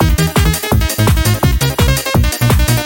fox.ogg